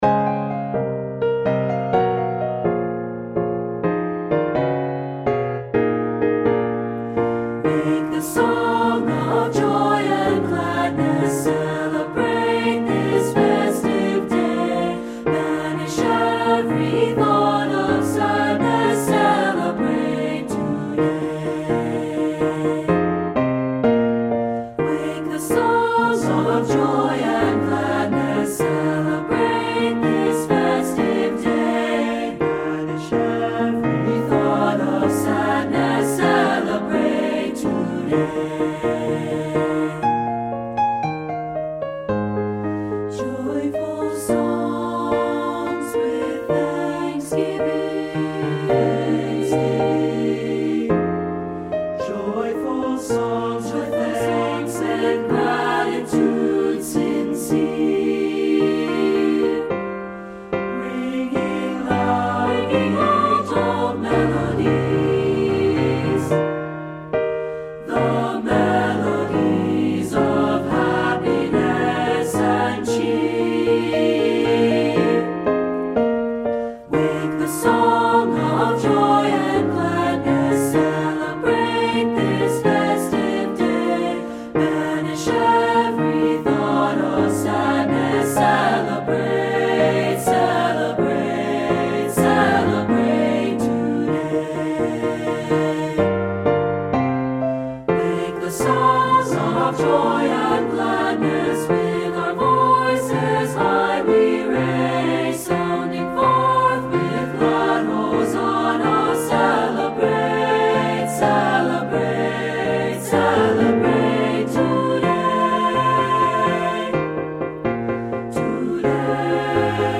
Studio Recording
Ensemble: Three-part Mixed Chorus
Key: E major
Accompanied: Accompanied Chorus